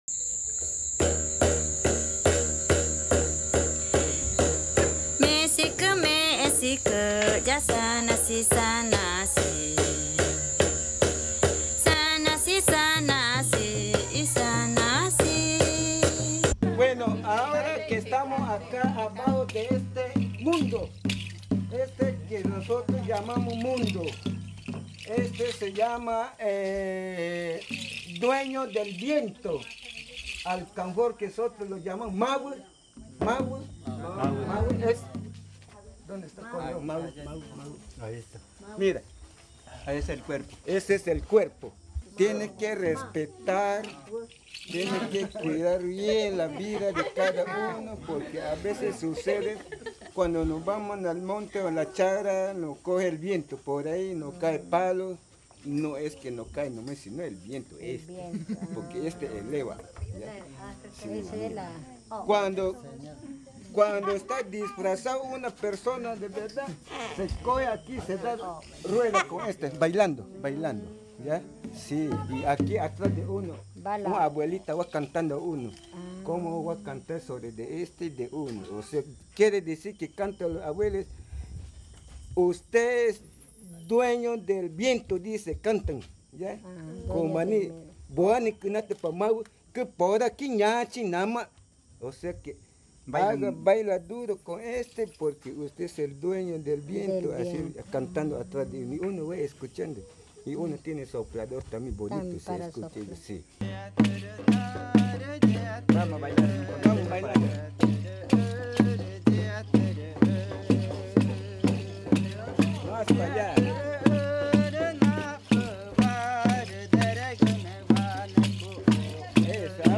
Canto de la rueda ritual Mawü
Leticia, Amazonas (Colombia)
Danza y canto del pueblo magütá (tikuna) con la rueda ritual Mawü (dueño del viento).